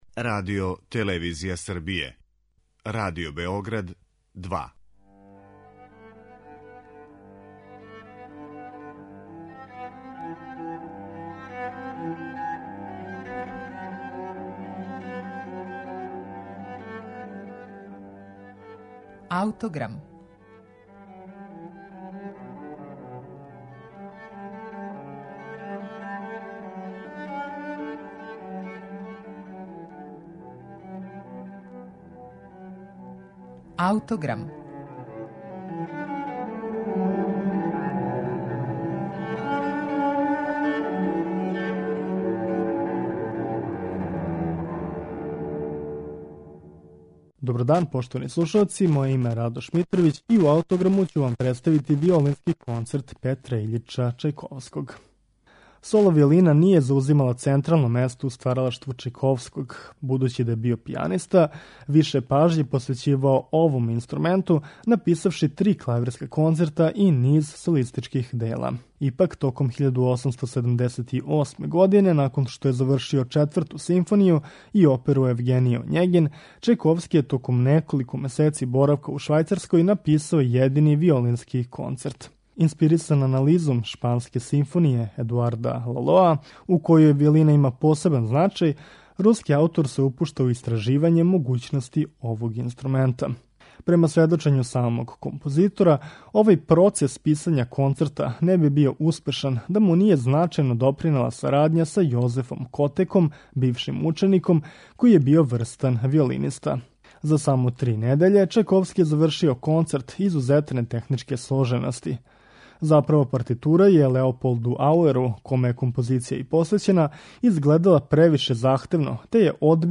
Једини виолински концерт Чајковског
Реч је о изузетно виртуозном делу, које ћете слушати у извођењу Џошуе Бела и оркестра Берлинске филхармоније, под управом Мајкла Тилсона Томаса.